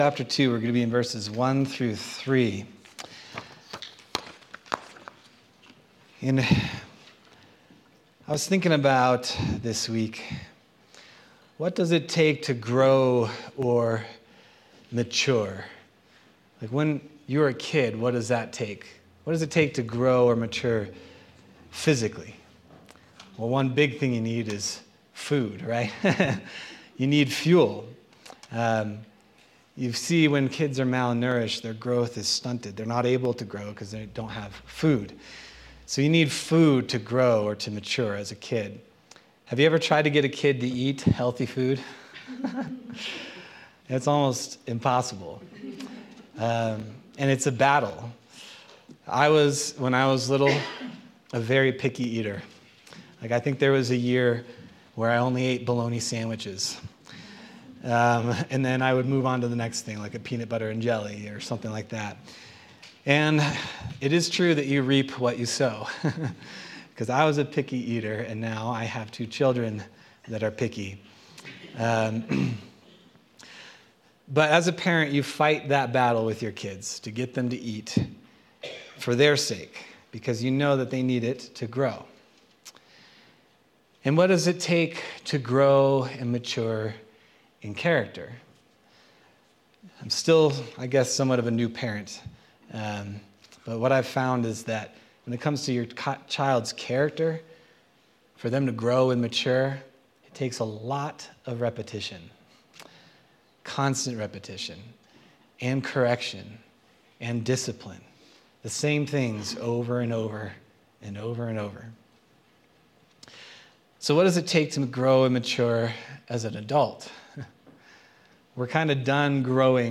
January 19th, 2025 Sermon